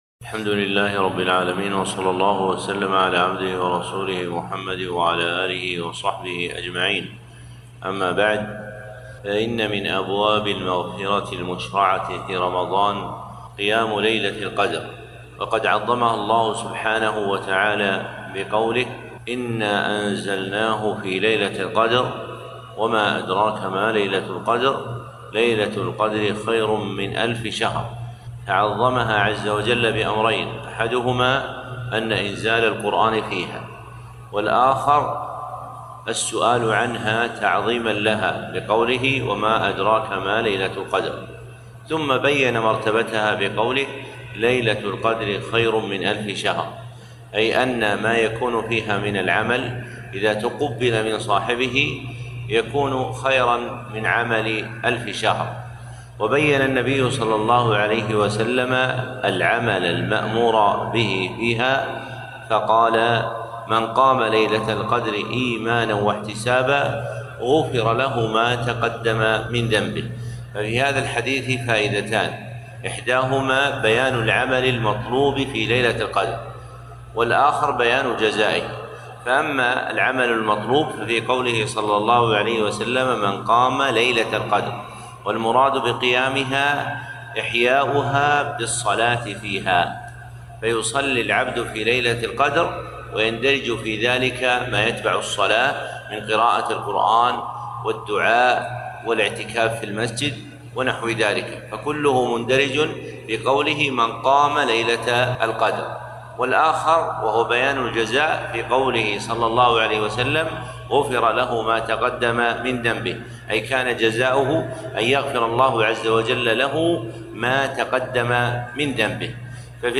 كلمة - في رحاب ليلة القدر